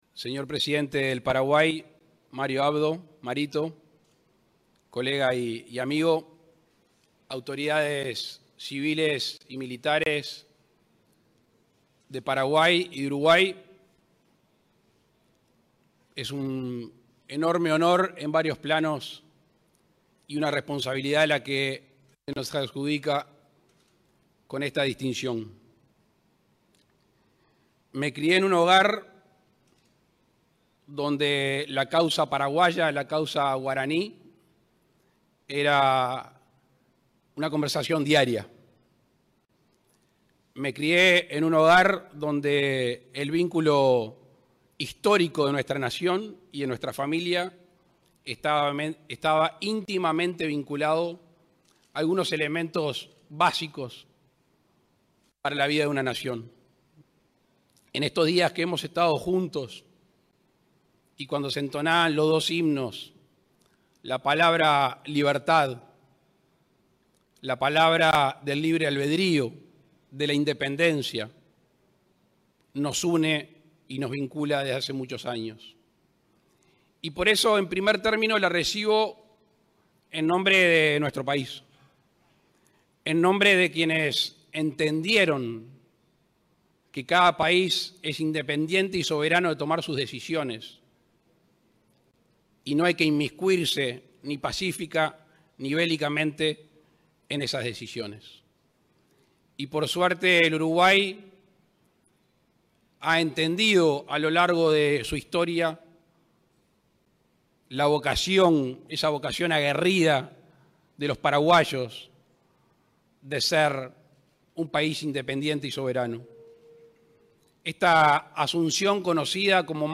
Palabras del presidente de la República, Luis Lacalle Pou, al recibir condecoración en Paraguay